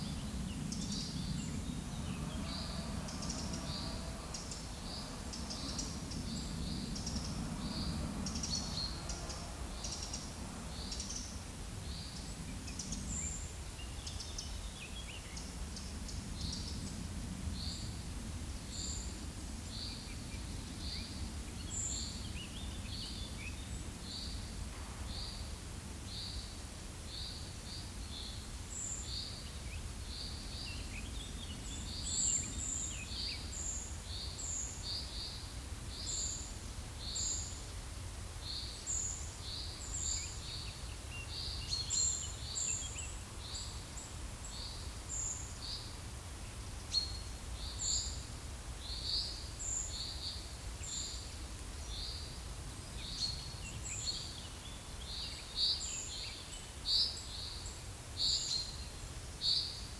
Certhia familiaris
Fringilla coelebs
Regulus ignicapilla
Troglodytes troglodytes